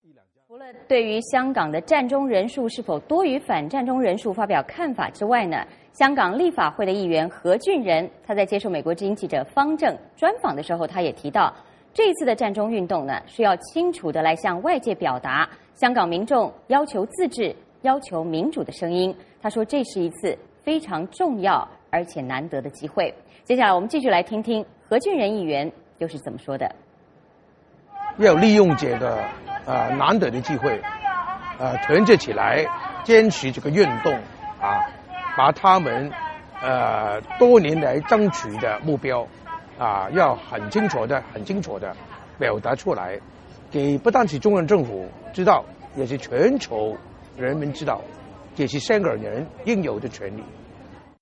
我们来听听何俊仁是怎么说的。